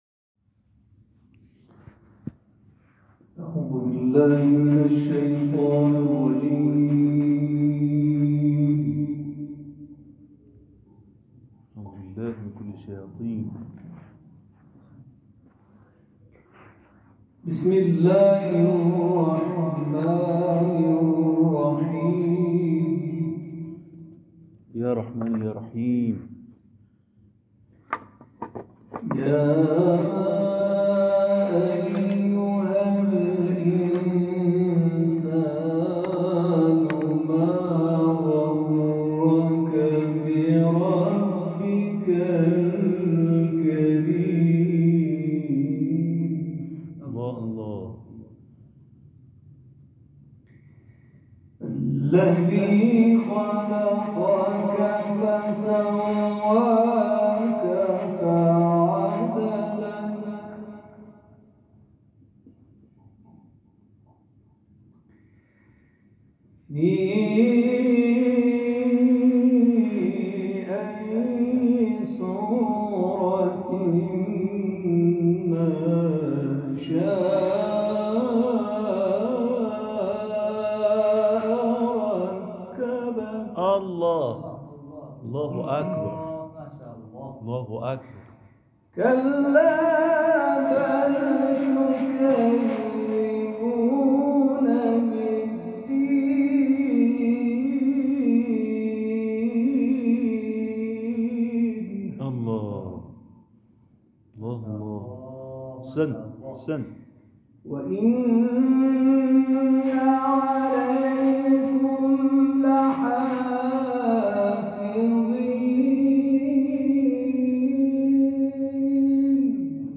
برچسب ها: تلاوت ، سوره انفطار ، سوره کوثر ، کردستان